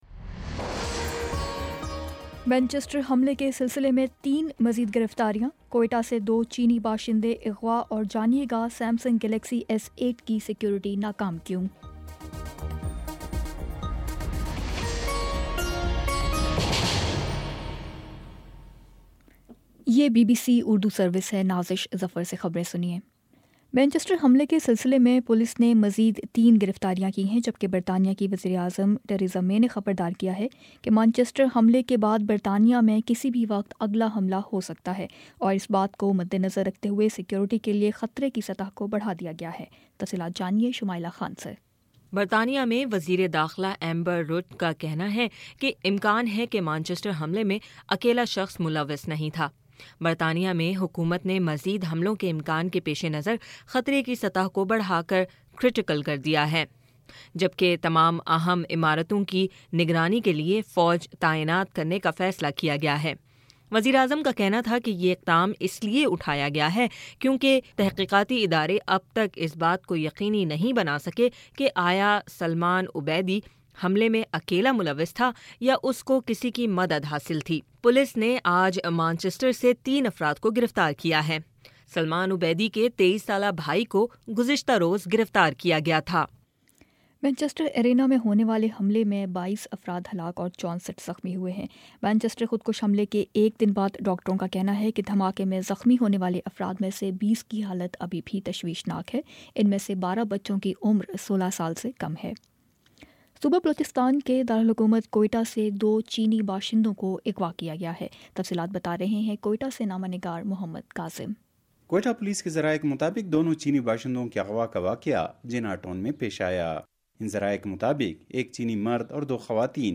مئی 24 : شام چھ بجے کا نیوز بُلیٹن